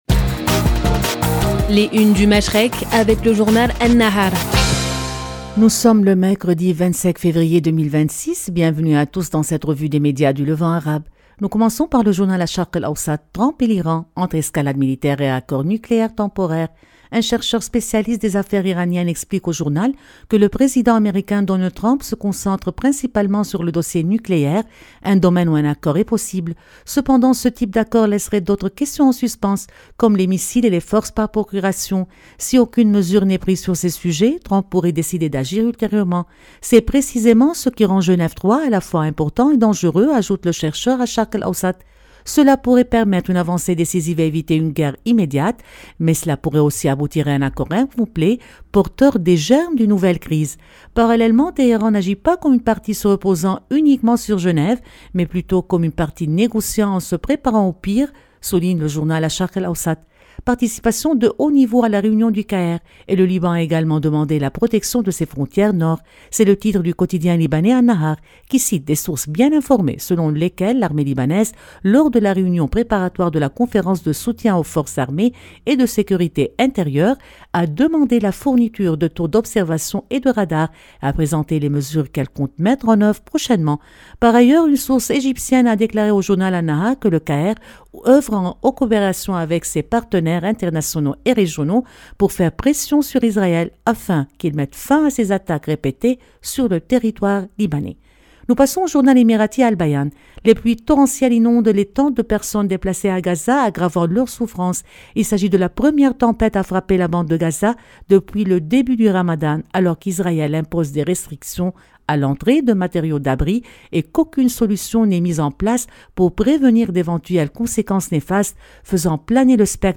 Revue de presse des médias du Moyen-Orient